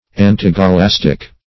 Search Result for " antigalastic" : The Collaborative International Dictionary of English v.0.48: Antigalastic \An`ti*ga*las"tic\, a. [Pref. anti- + Gr.